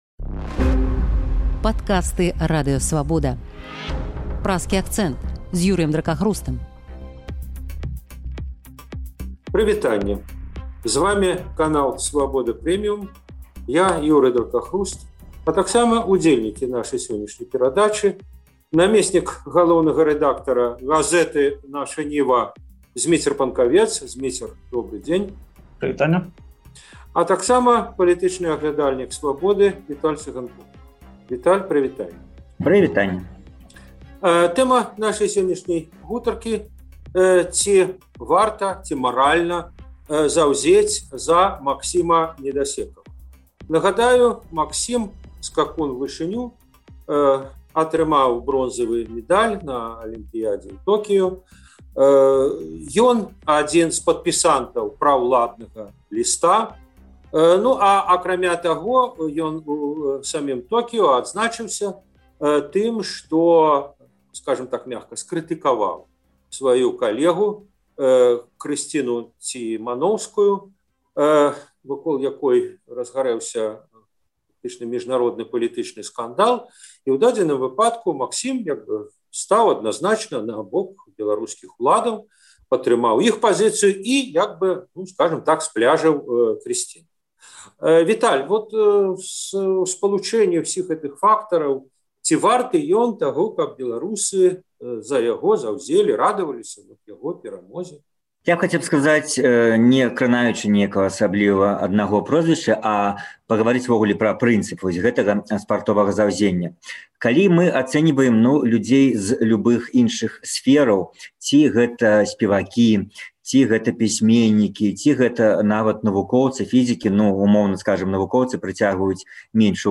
Па гэтых тэмах у Праскім акцэнце спрачаюцца